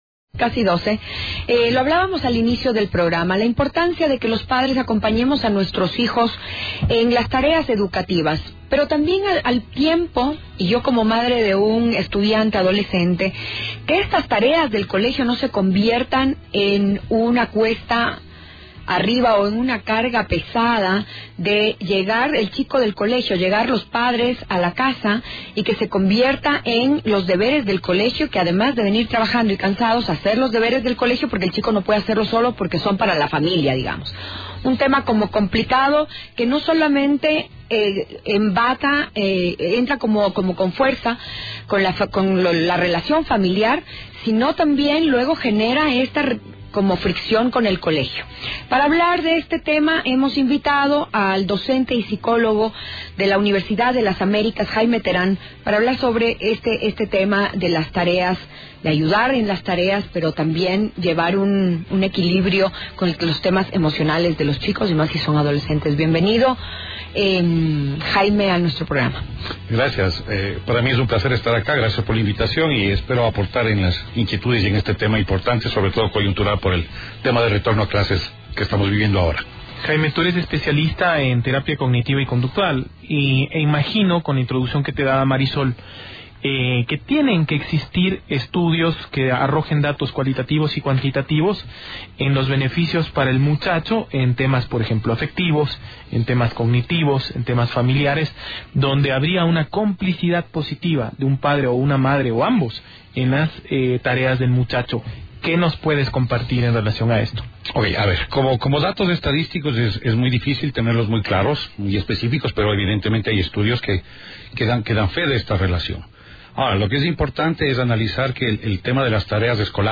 En una entrevista realizada en las cabinas de Radio Quito-Platinum